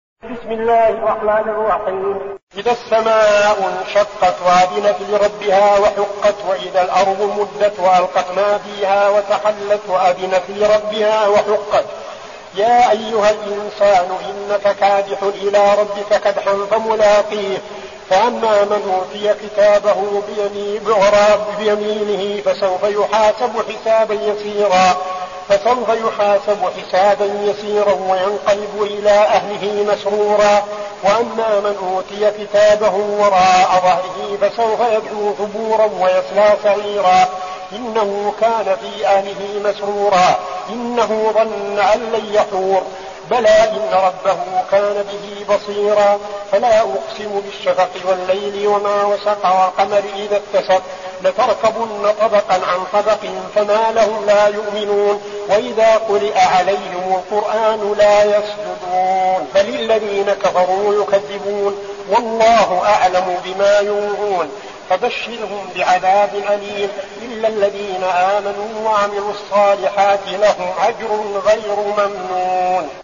المكان: المسجد النبوي الشيخ: فضيلة الشيخ عبدالعزيز بن صالح فضيلة الشيخ عبدالعزيز بن صالح الانشقاق